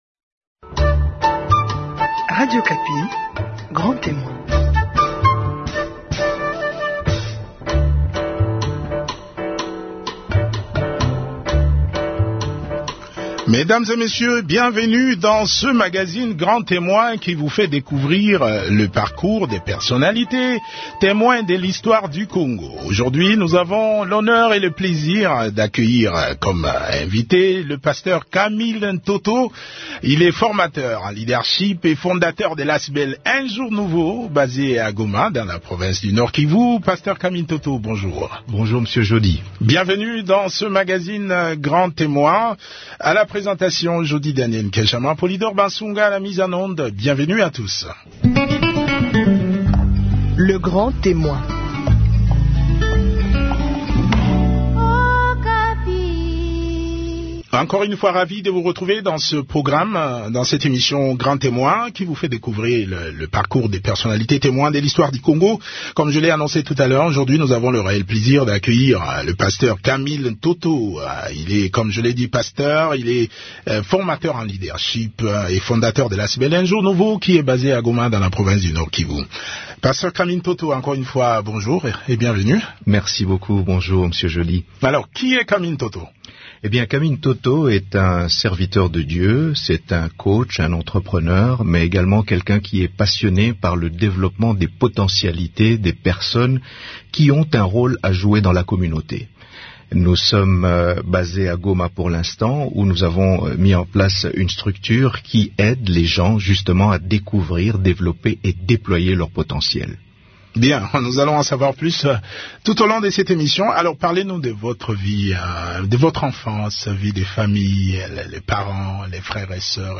Cependant, cela exige un leadership efficace dans tous les domaines pour mieux positionner la RDC dans le concert des Nations. Suivez l’entretien